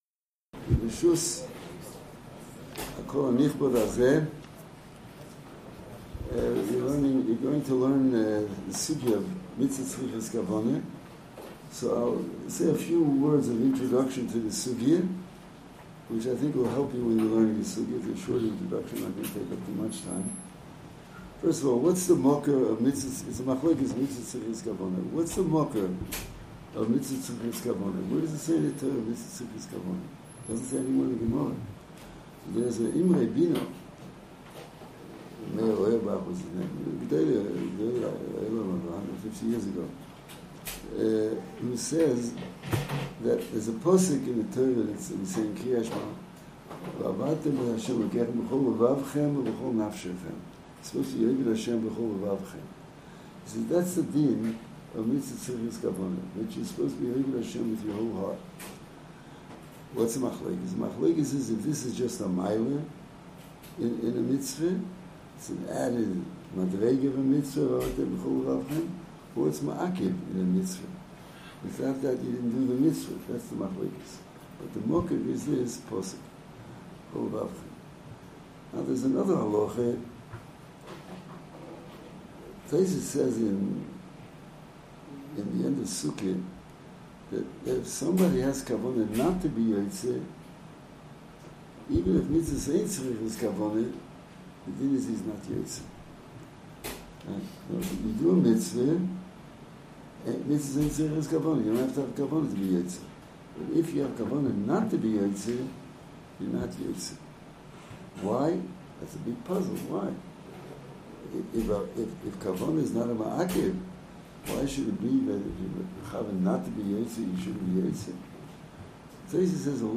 Audio of the Rosh Hayeshiva’s Hakdama Shiur